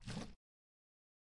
描述：一瓶水声
Tag: 塑料瓶